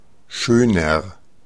Ääntäminen
Ääntäminen Tuntematon aksentti: IPA: /ˈʃøːnɐ/ Haettu sana löytyi näillä lähdekielillä: saksa Käännöksiä ei löytynyt valitulle kohdekielelle. Schöner on sanan schön komparatiivi.